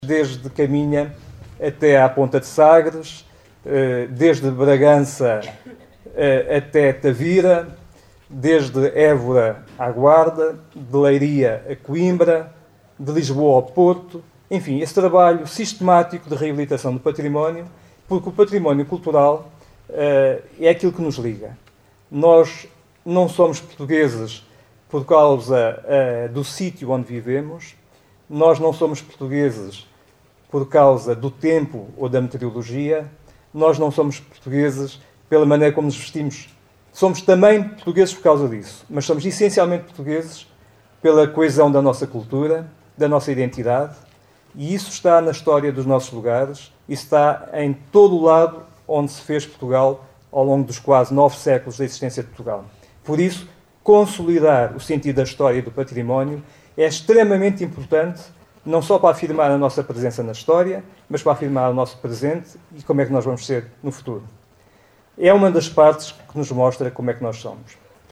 Por seu lado, Jorge Barreto Xavier realçou a importância do Mosteiro enquanto referência maior na história do românico. O secretário de Estado da Cultura defendeu a relevância de todo o trabalho de reabilitação do património
rm_sec_estado_mosteiro.mp3